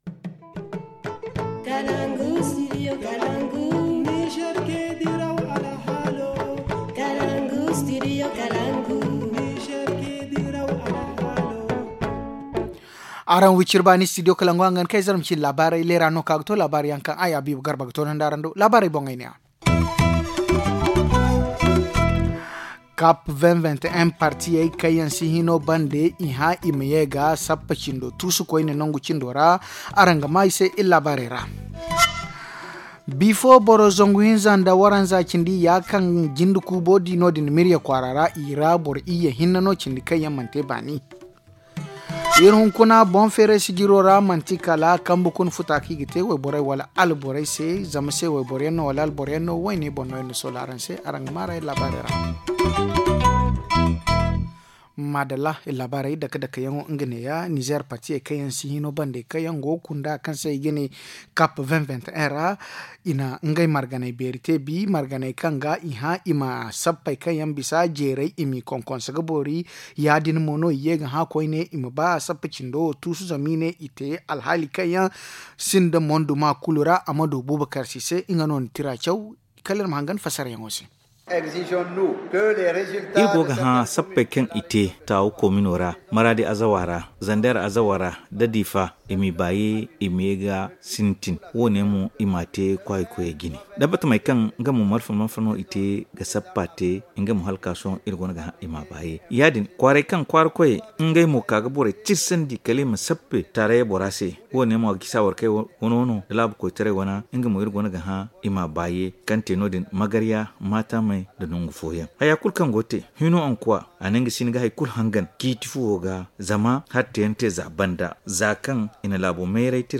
Le journal du 12 janvier 2021 - Studio Kalangou - Au rythme du Niger